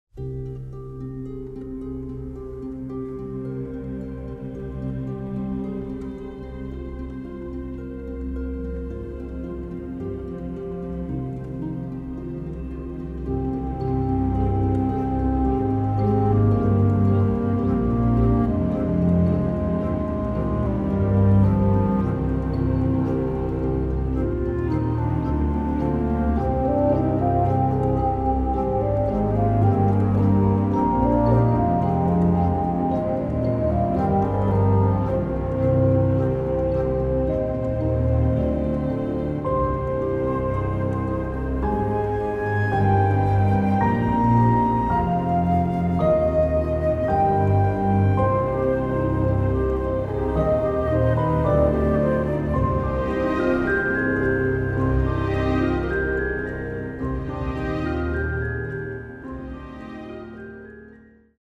emotionally-charged music
top-notch full-bodied orchestral composition